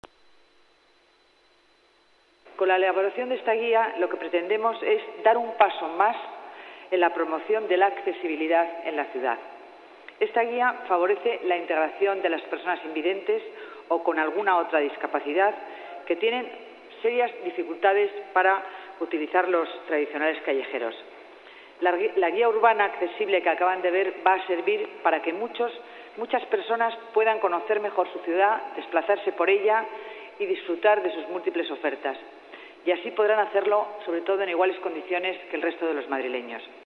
Nueva ventana:Declaraciones de Dancausa